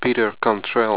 Pronounced